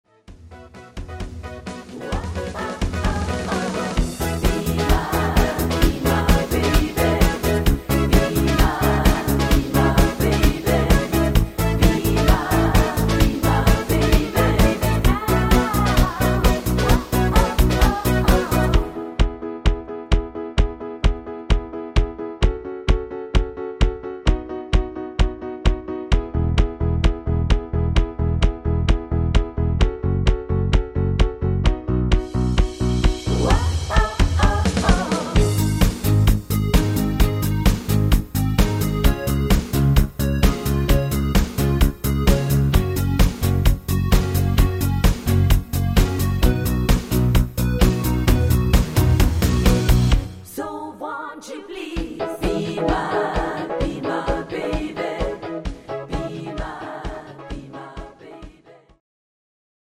Rhythmus  Discofox
Art  Englisch, Schlager 2010er, Weibliche Interpreten